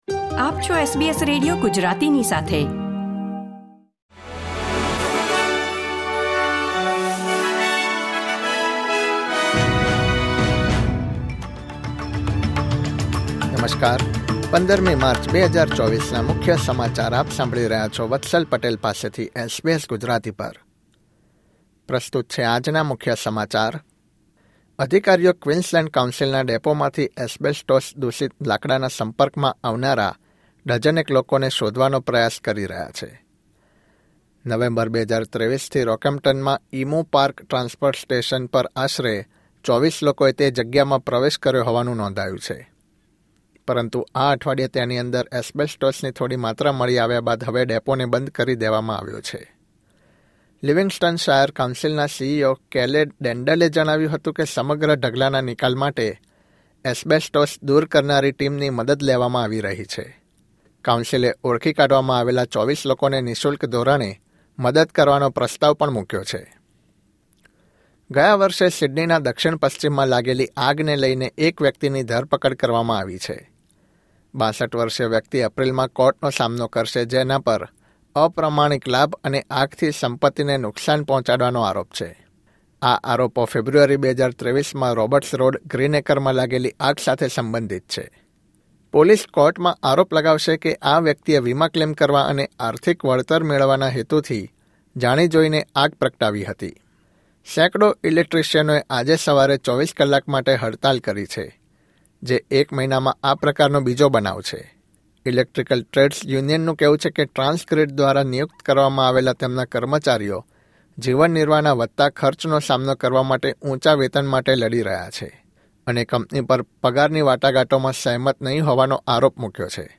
SBS Gujarati News Bulletin 15 March 2024